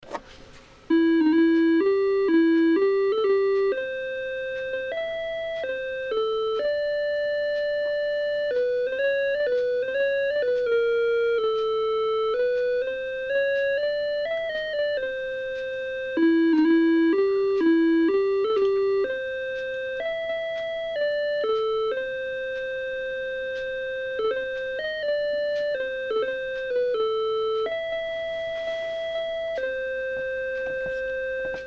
Intro music